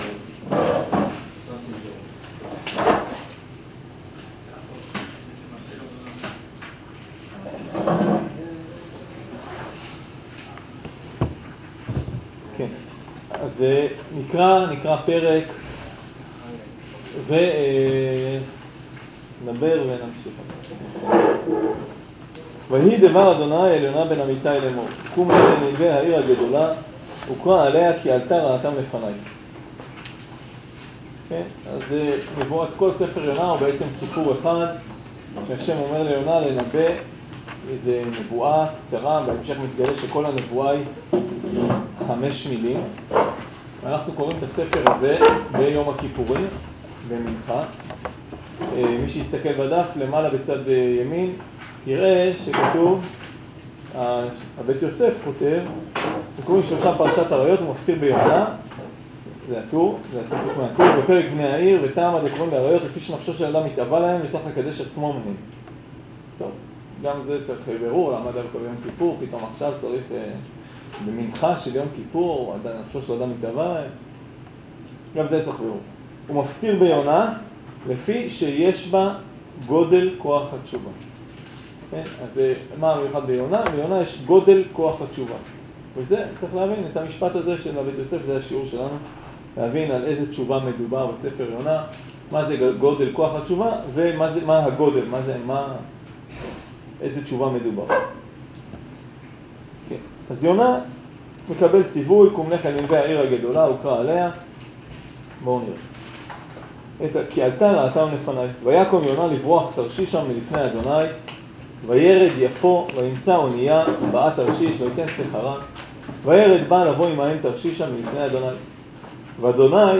שיעור הכנה ליום כיפור על מהות התשובה על פי ספר יונה